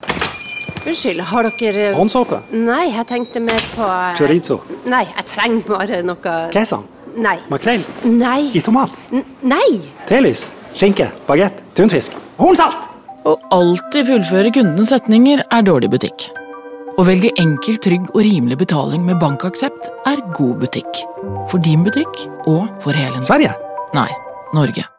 Vi liker spesielt godt den avslepne voice’en som svarer fint på dramatiseringen, fremfor en klassisk reklamevoice.